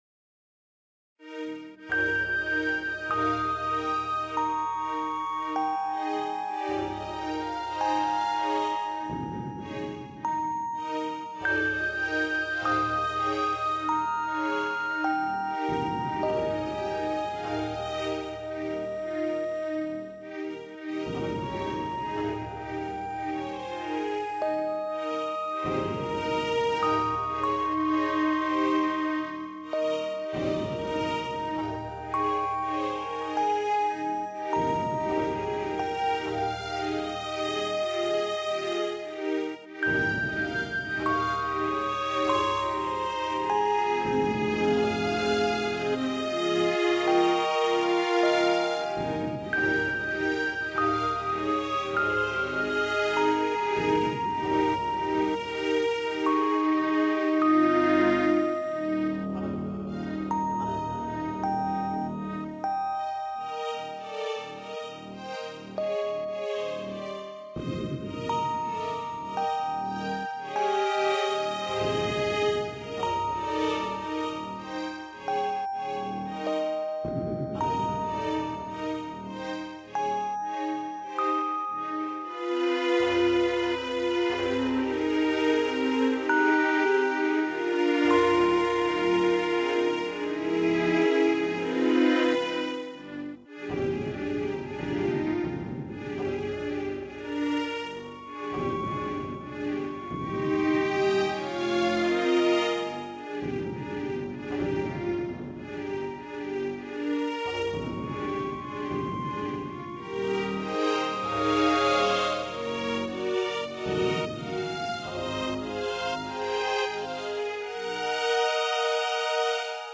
The heavy thumping is to simulate the Dragon footsteps moving around in the area.